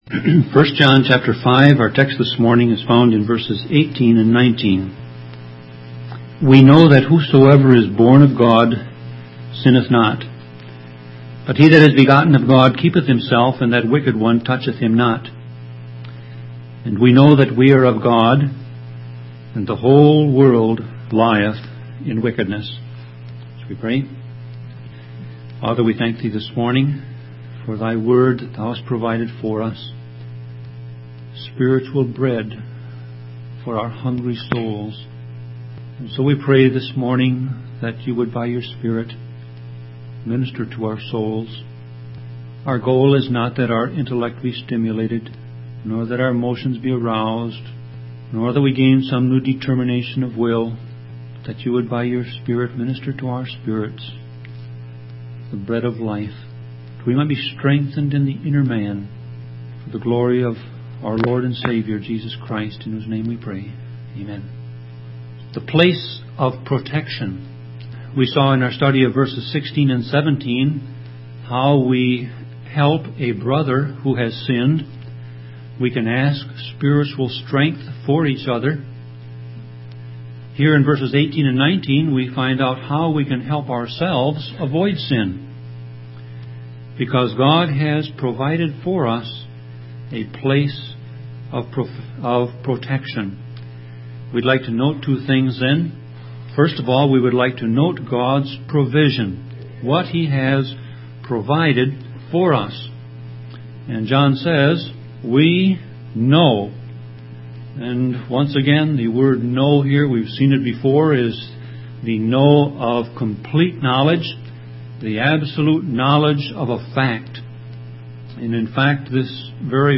Sermon Audio Passage: 1 John 5:18-19 Service Type